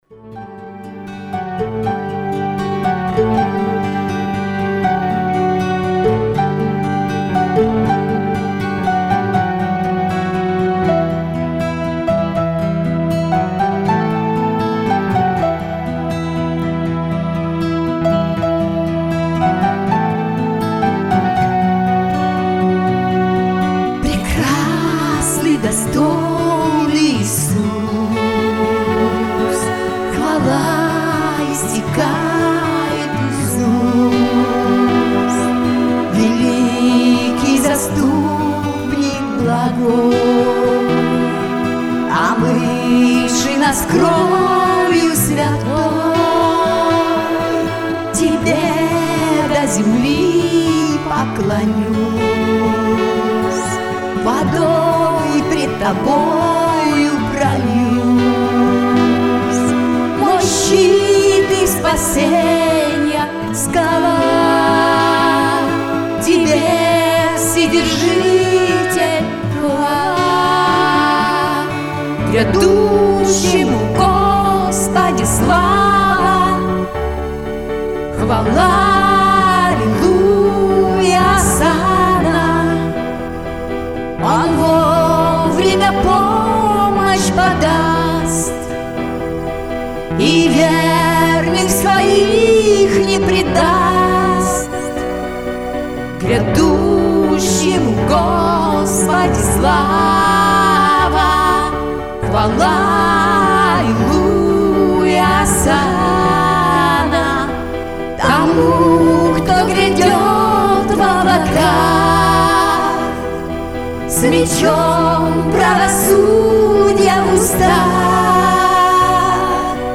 Исполняет дуэт